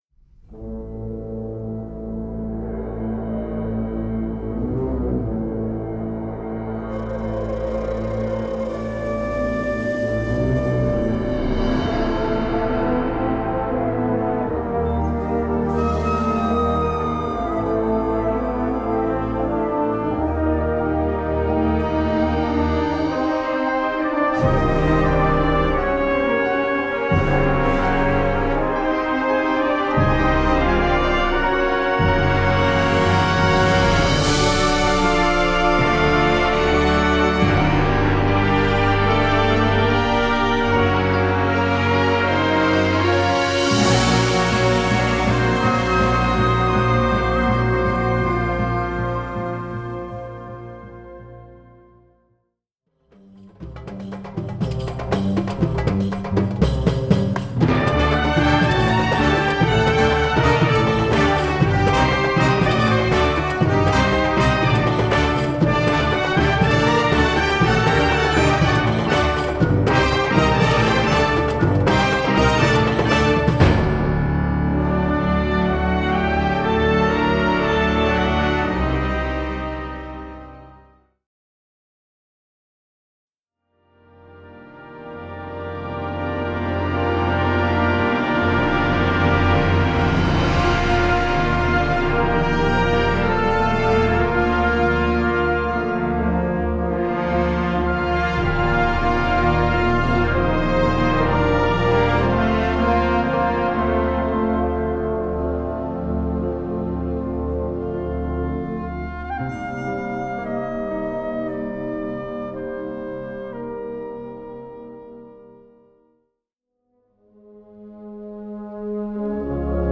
Categoria Concert/wind/brass band
Sottocategoria Musica contemporanea (1945-oggi)
Instrumentation Ha (orchestra di strumenti a faito)